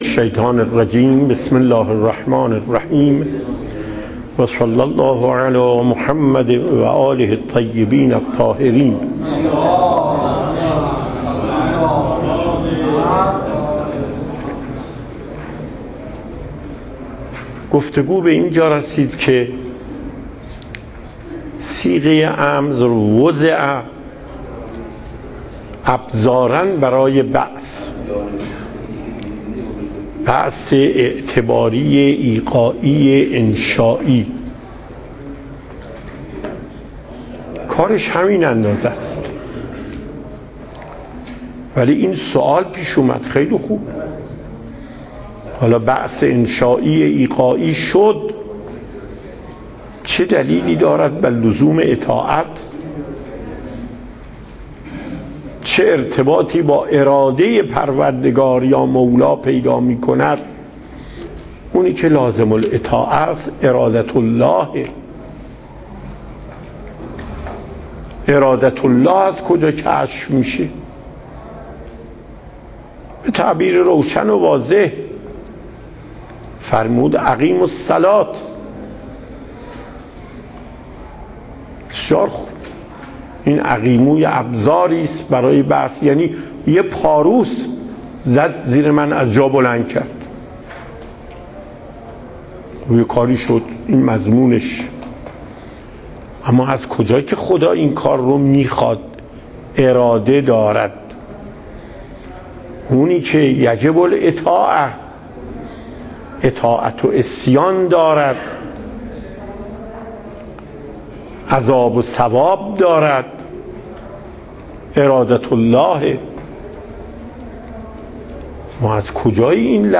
صوت درس
درس اصول آیت الله محقق داماد